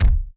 Heavy_Step02.wav